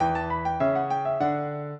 piano
minuet1-2.wav